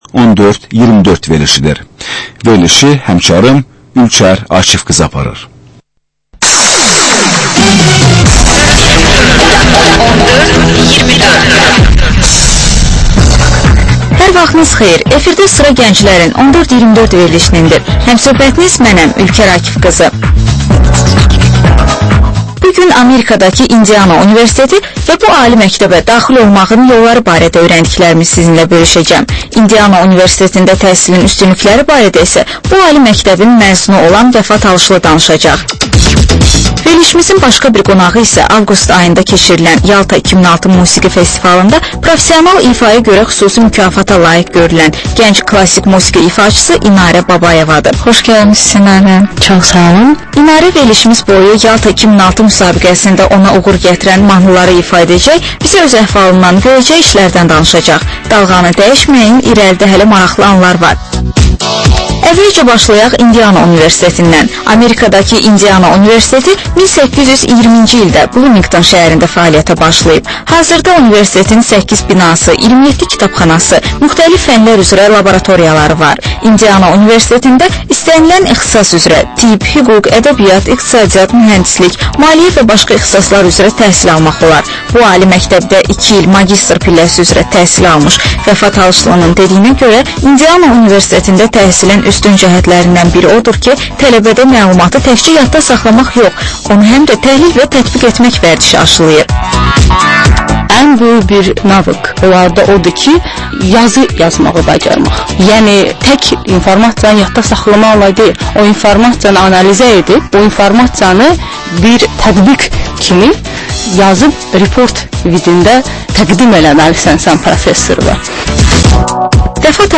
Reportajç müsahibə, təhlil